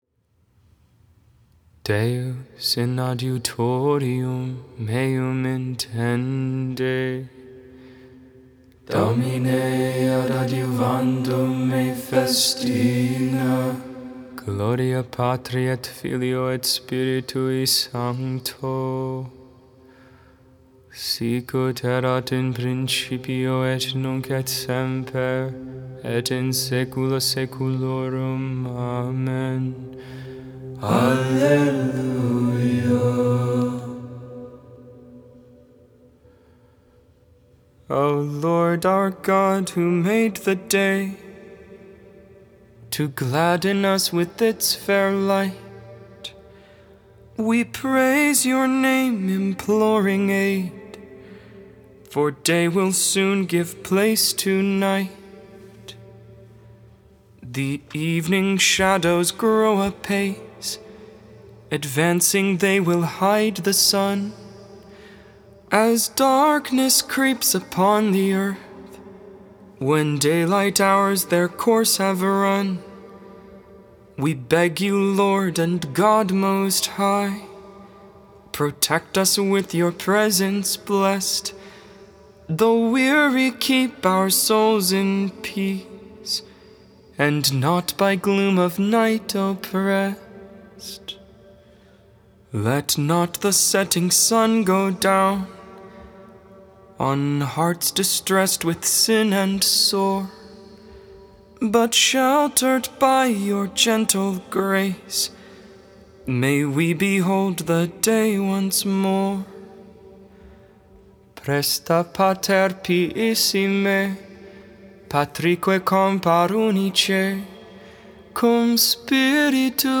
8.4.22 Vespers, Thursday Evening Prayer
Psalm 72 (part 1, tone 2) Psalm 72 (part 2, tone 6)